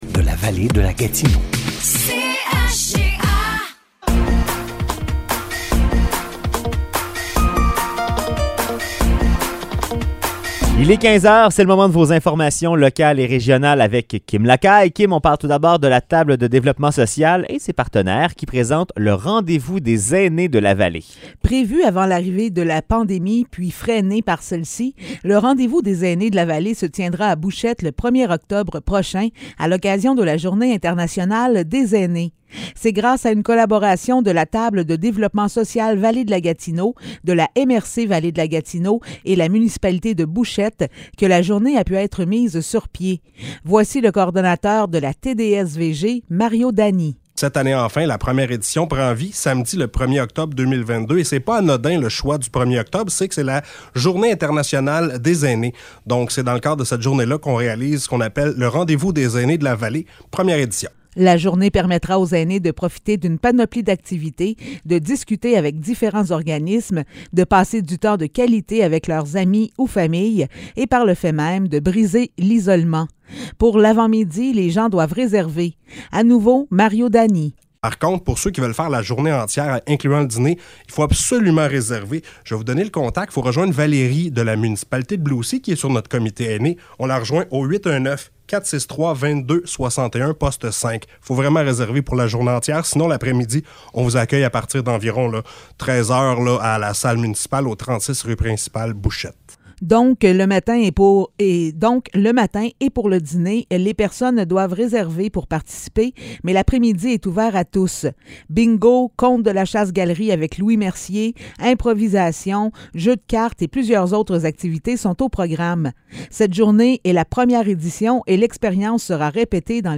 Bulletins de nouvelles